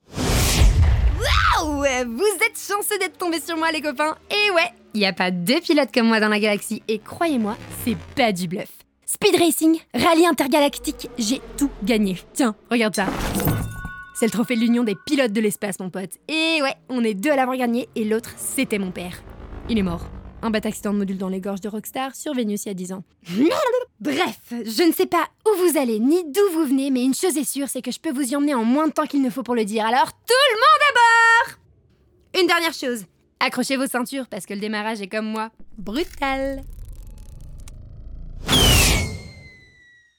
Cartoon
Voix off